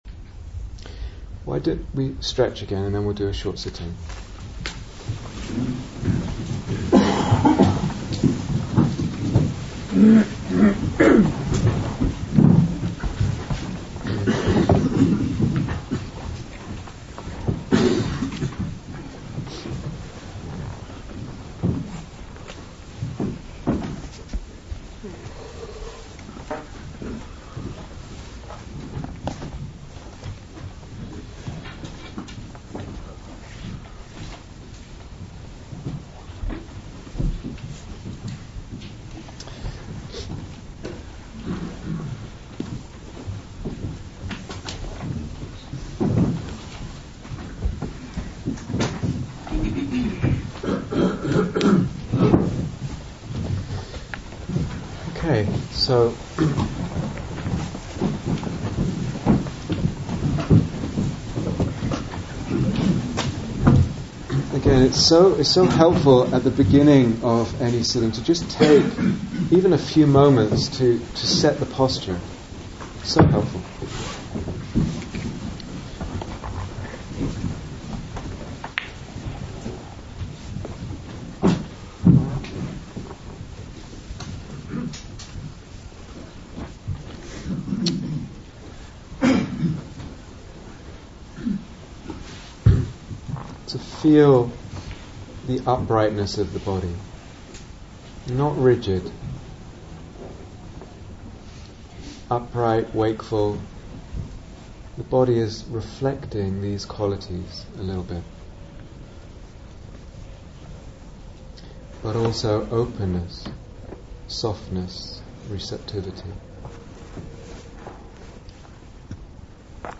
Guided Meditation 2
Guided Meditation 2 Download 0:00:00 --:-- Date 7th December 2014 Retreat/Series Day Retreat, London Insight 2014 Transcription Why don't we stretch again, and then we'll do a short sitting?